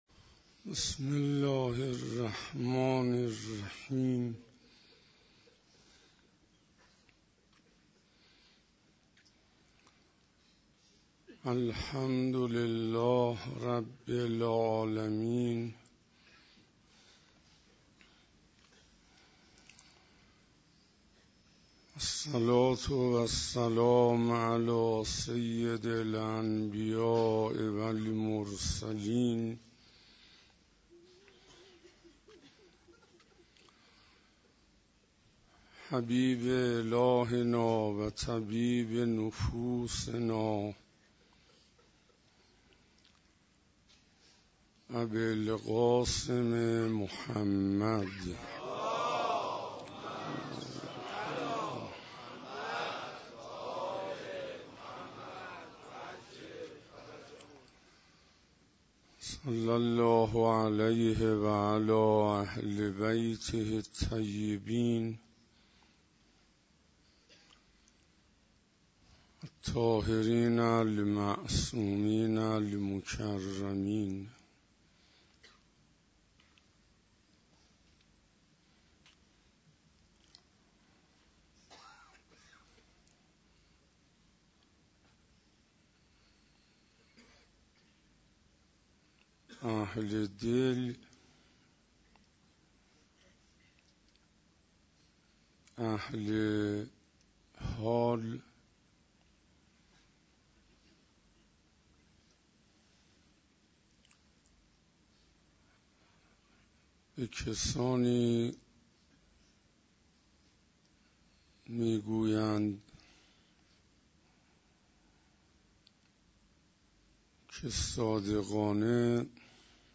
روز اول محرم 97 - حسینیه هدایت - عشق حقیقی